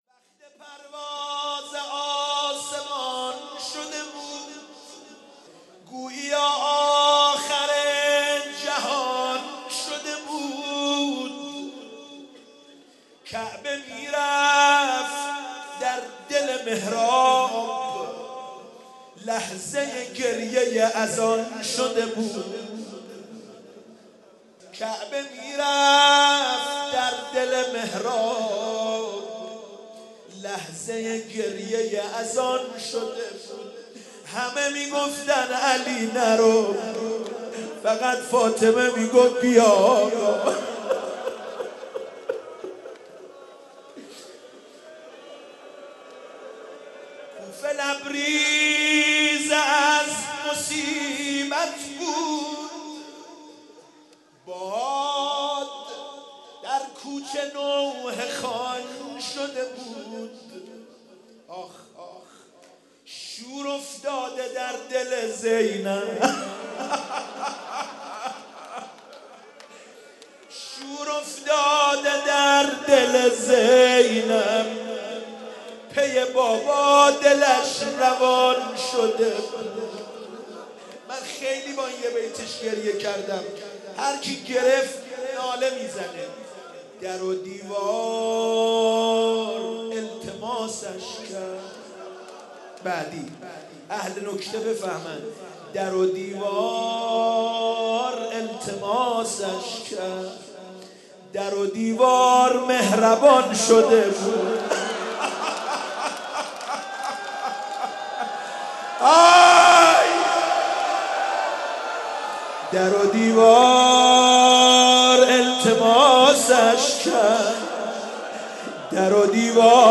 شب 19 ماه مبارک رمضان 96(قدر) - روضه - مثل پرواز آسمان شده بود
روضه - مثل پرواز آسمان شده بود
مداحی